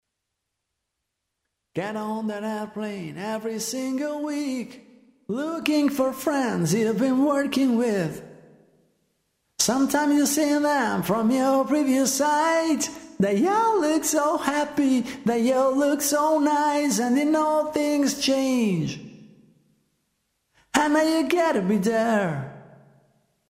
MaleVox
Le tracce sono state elaborate inserendo/escludendo il compressore in modo da far apprezzare le differenze e il contributo dell’effetto sul suono.
MaleVox.mp3